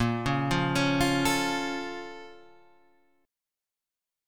A# Minor Major 7th